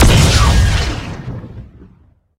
gauss_shot.ogg